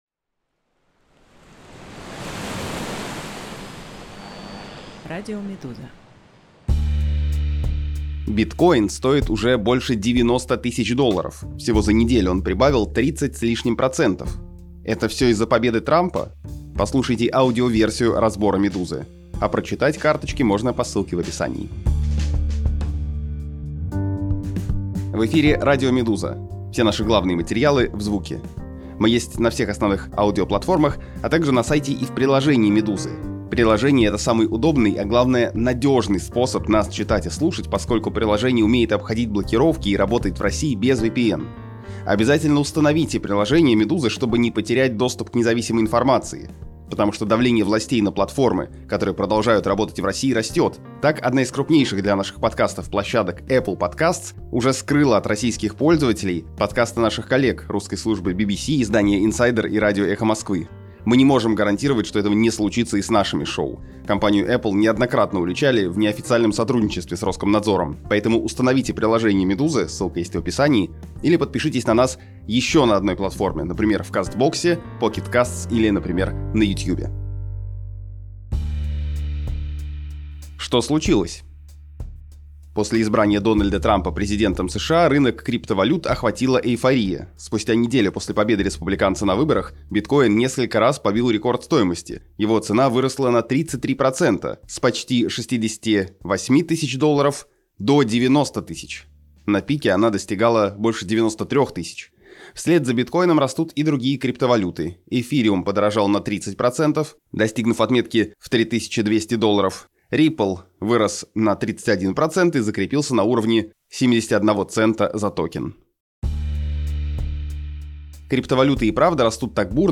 Аудиоверсии главных текстов «Медузы».